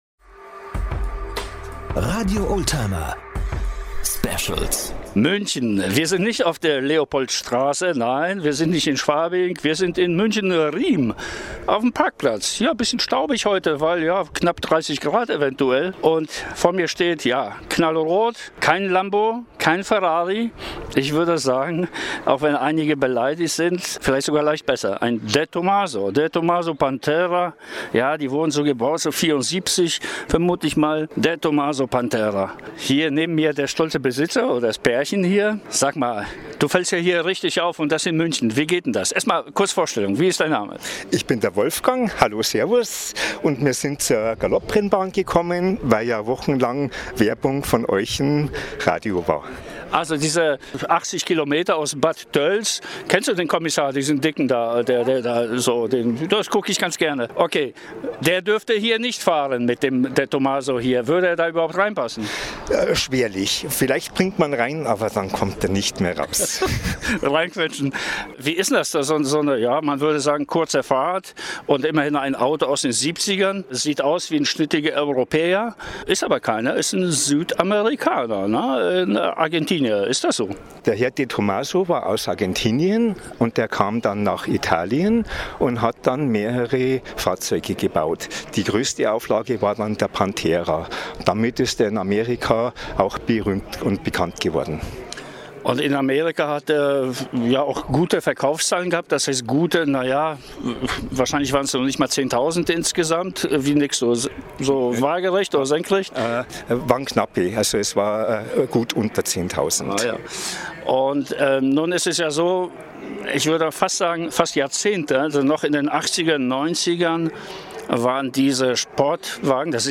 - Interviews & Reportagen | RADIO OLDTIMER